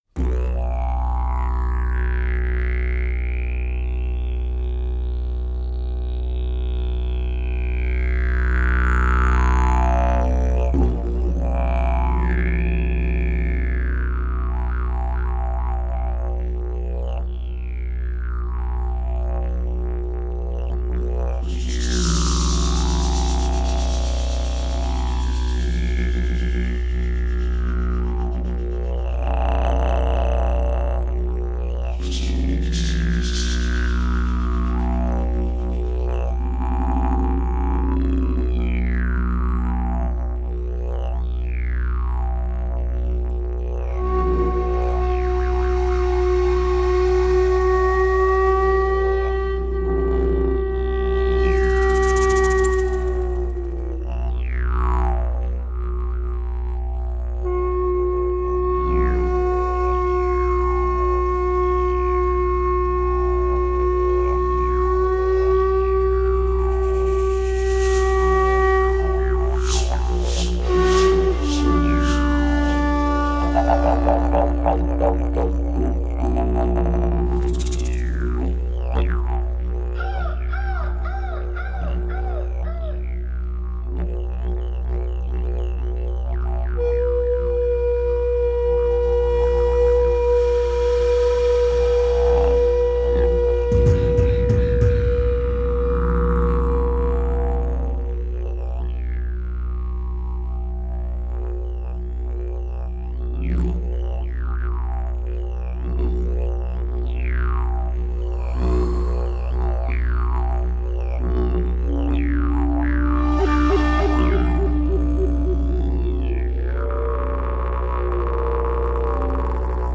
For Shakuhachi, Didgeridoo and Beatbox